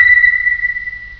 SONAR.wav